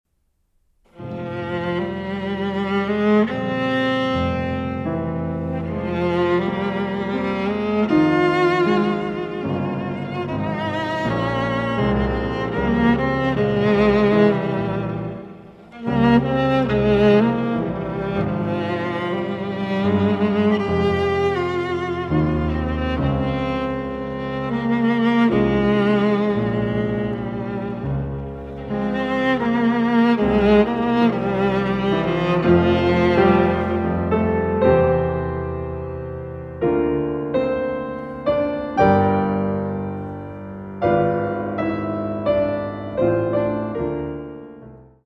for viola and piano